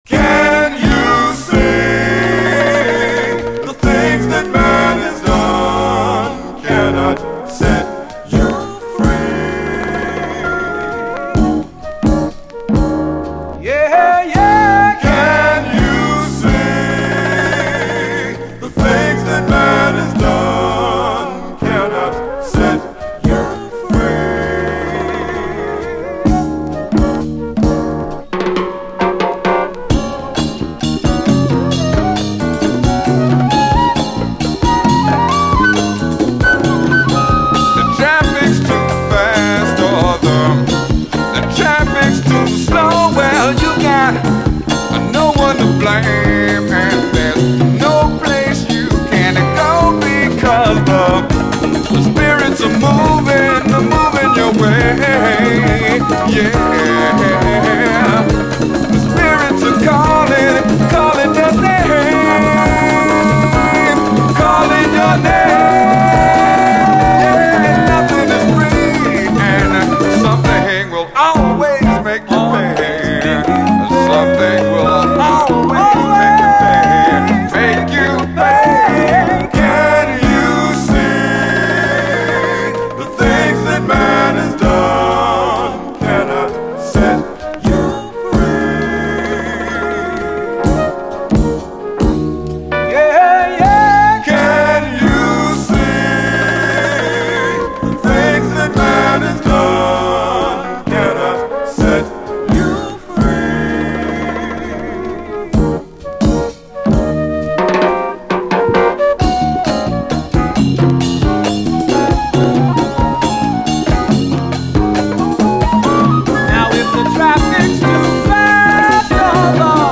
SOUL, 70's～ SOUL, 7INCH
75年のスピリチュアル・ブラジリアン・メロウ・ファンク！
メロウな展開と疾走するブラジリアン・グルーヴを行き来するレアグルーヴ・トラック！